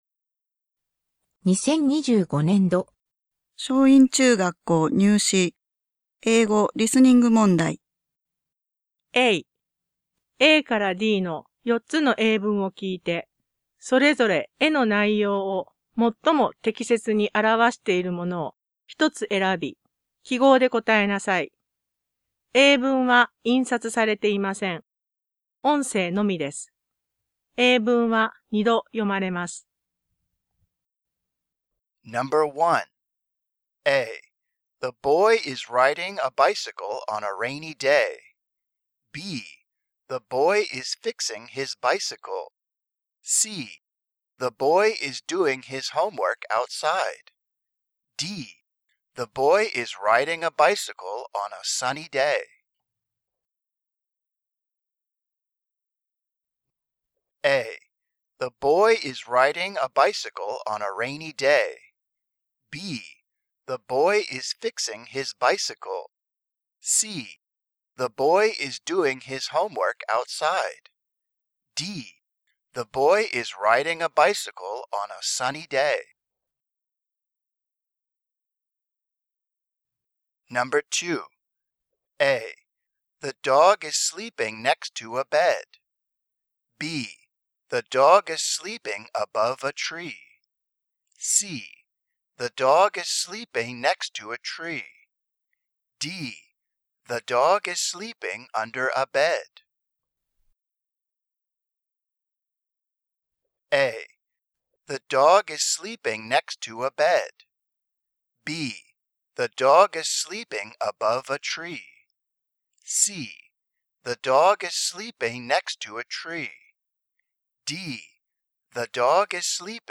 リスニング問題音源
英語入試リスニング（DS志望/GS志望共通）